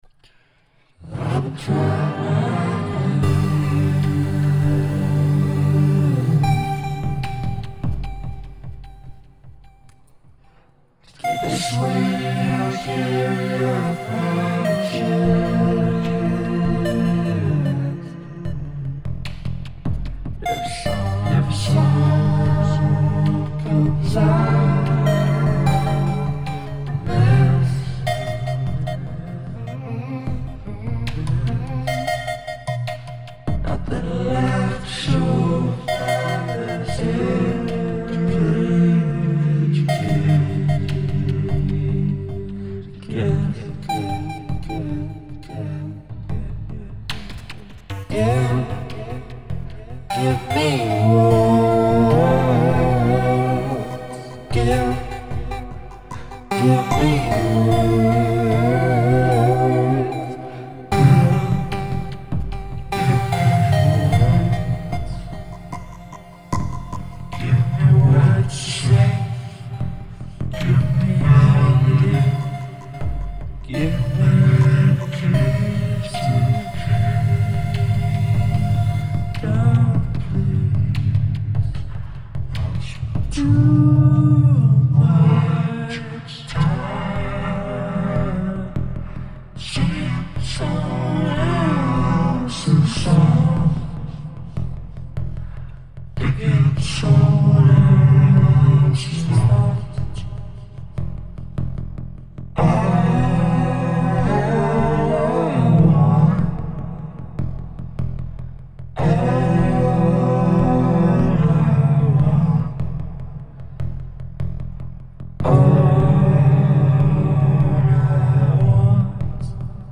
Vocal Harmony